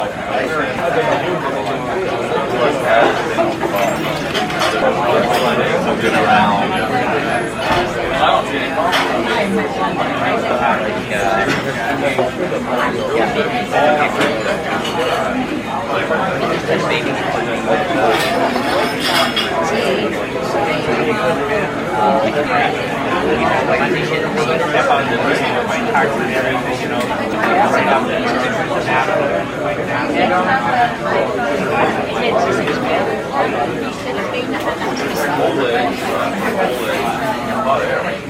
Indoor atmospheres 3